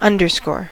underscore: Wikimedia Commons US English Pronunciations
En-us-underscore.WAV